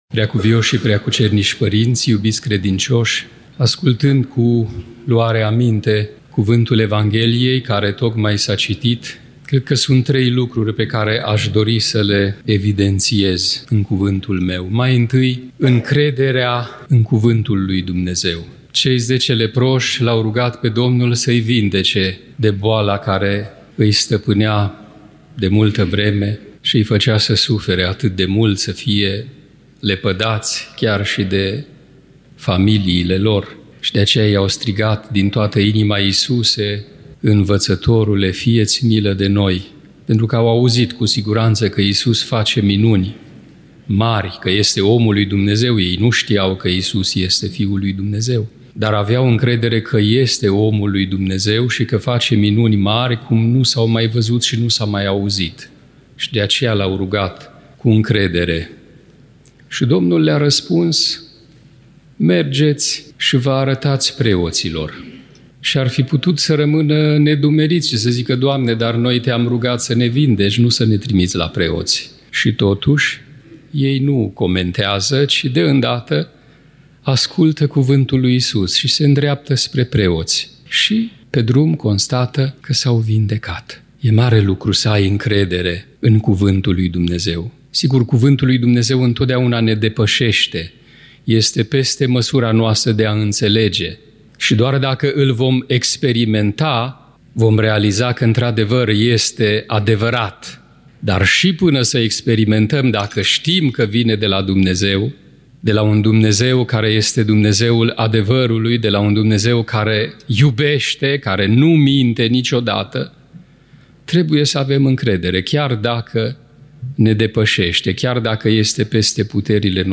Cuvântul de învățătură al Preasfințitului Părinte Teofil de Iberia, Arhiereul-vicar al Episcopiei Spaniei și Portugaliei, rostit în Catedrala Mitropolitană